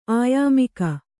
♪ āyāmika